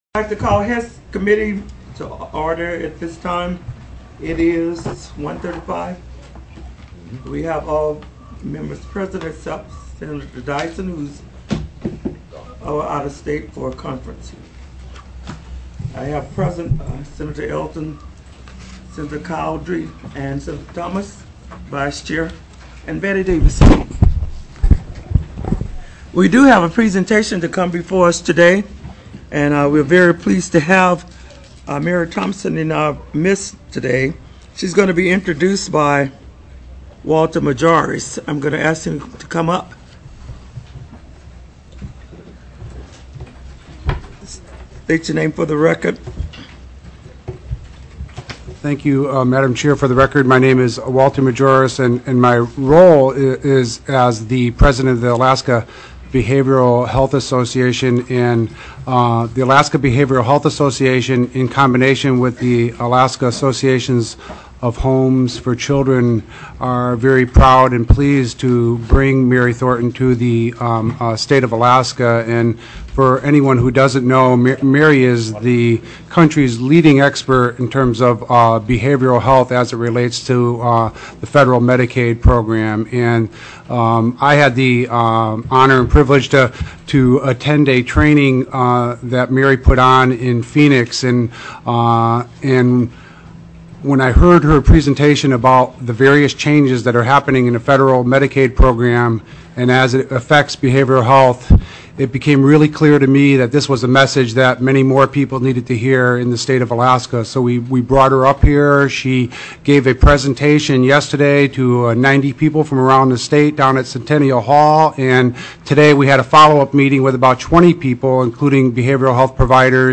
03/05/2008 01:30 PM Senate HEALTH, EDUCATION & SOCIAL SERVICES
Medicaid Behavior Health Presentation
TELECONFERENCED